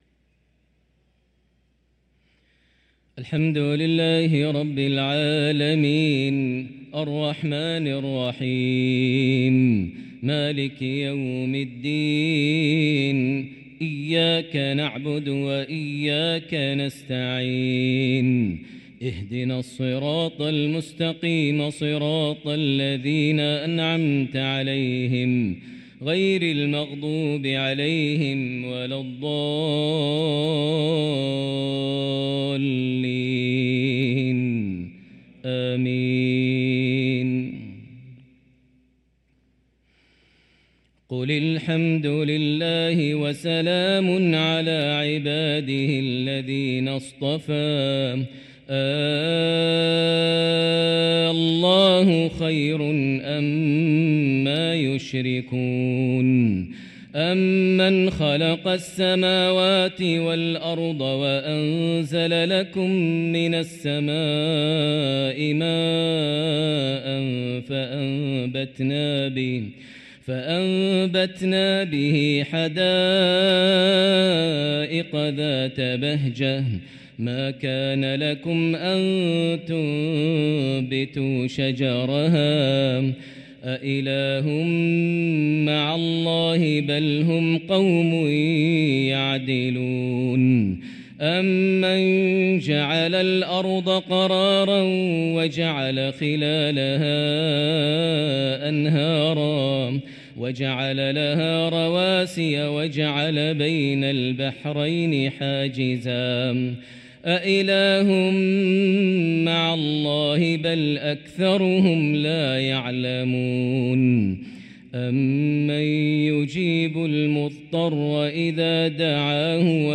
صلاة العشاء للقارئ ماهر المعيقلي 17 ربيع الآخر 1445 هـ
تِلَاوَات الْحَرَمَيْن .